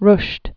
(rsht)